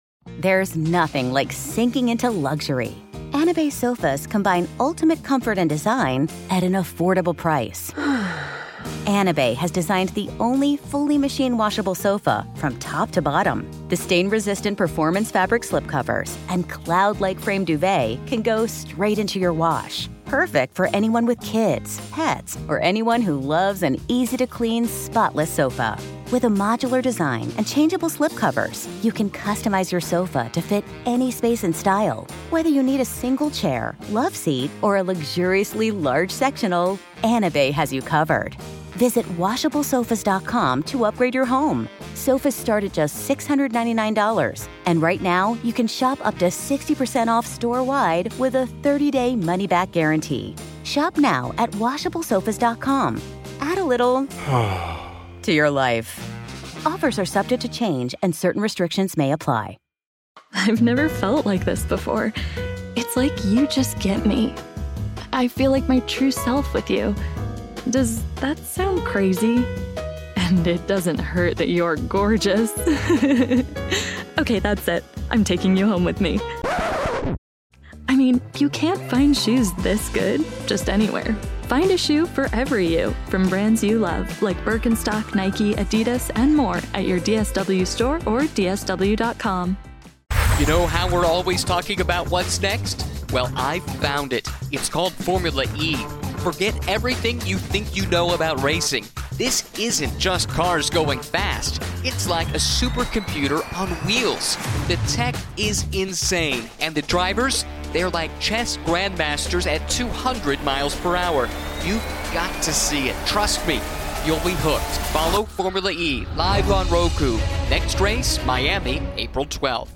The Story of How One Bad Employee Harmed a Bank's Reputation (Told by the Ritz-Carlton's Founder)